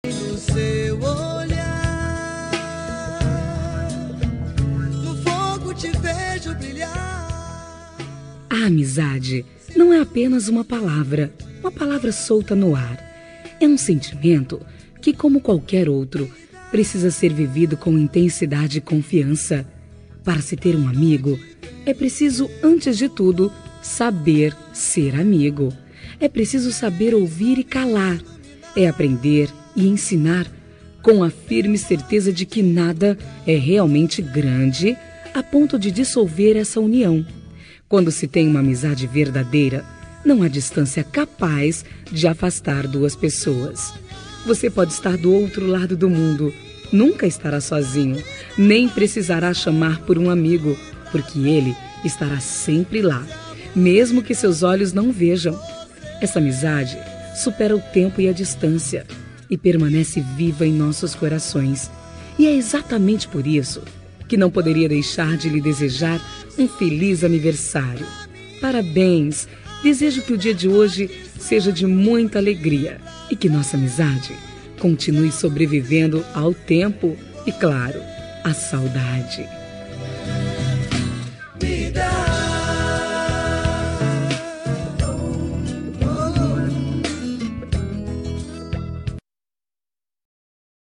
Telemensagem de Aniversário de Pessoa Especial – Voz Feminina – Cód: 202221 Distante